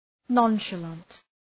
{,nɒnʃə’lɒnt}
nonchalant.mp3